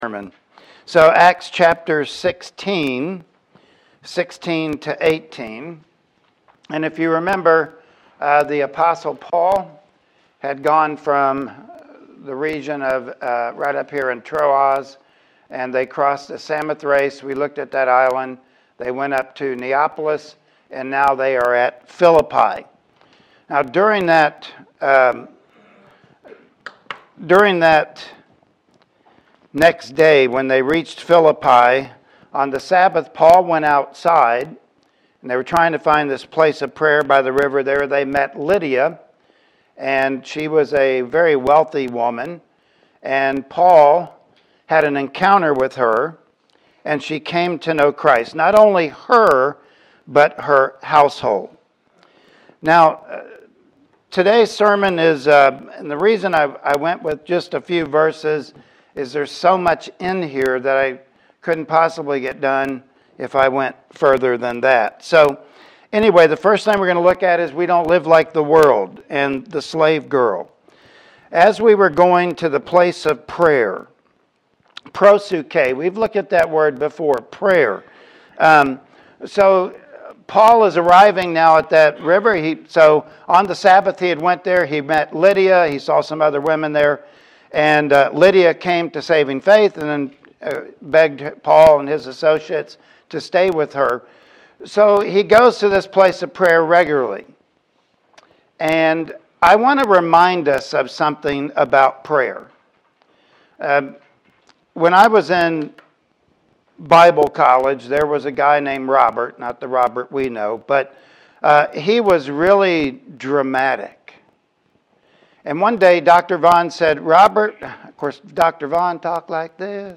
Acts 16:16-18 Service Type: Sunday Morning Worship Service Topics: Divination « Led by the Spirit